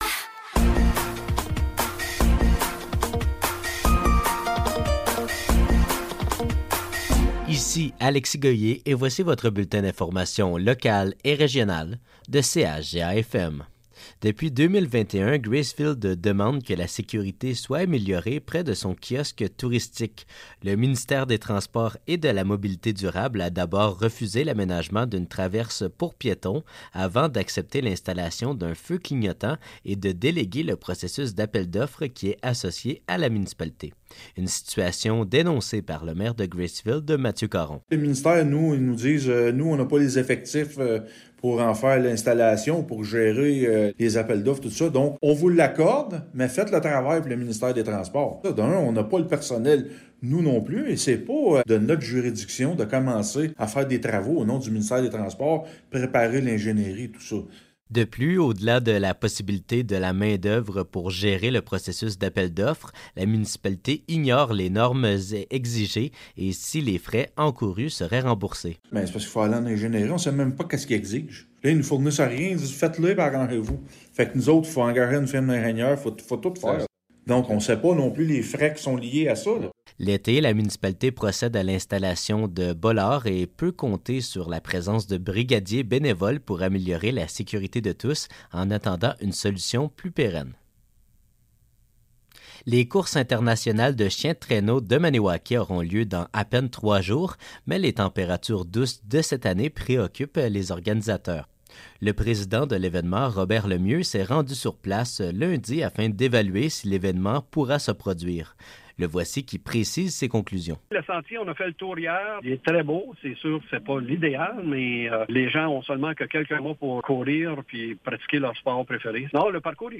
Nouvelles locales - 14 février 2024 - 12 h